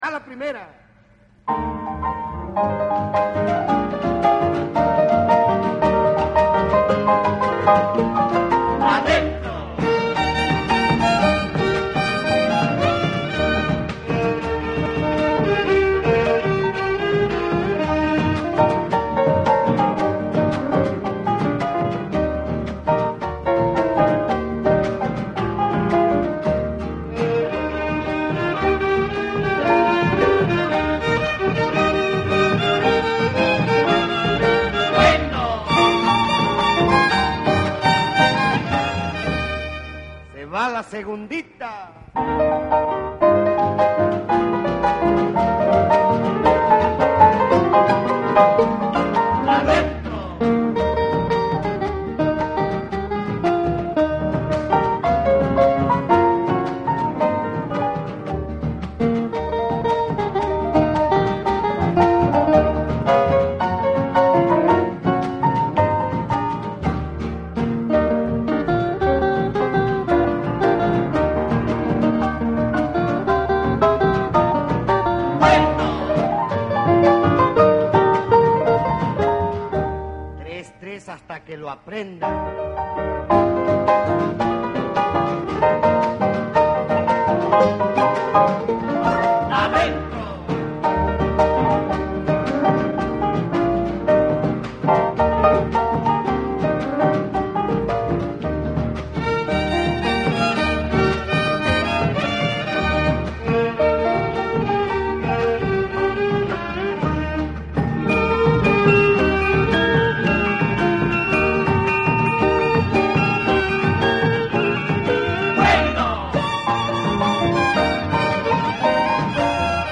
Aquí se dejan dos audios como ejemplos,  extraídos del LP.
danza